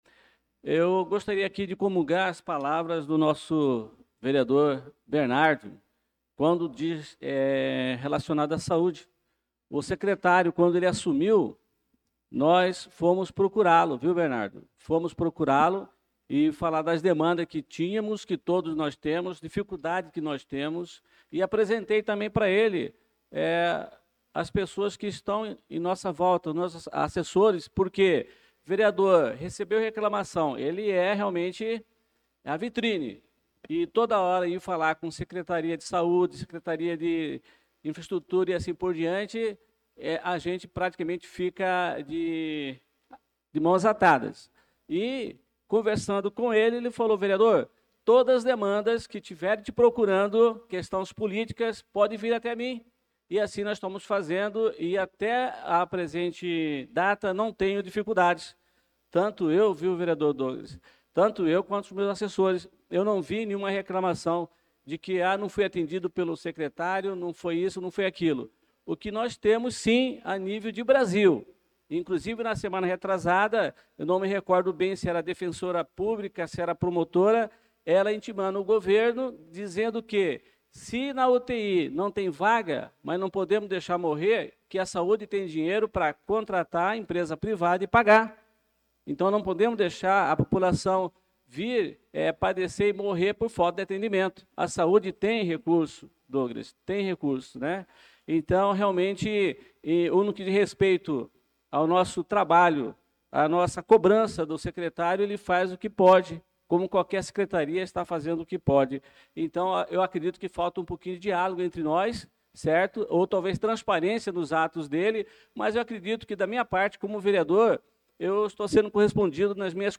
Pronunciamento do vereador Adelson Servidor na Sessão Ordinária do dia 25/08/2025.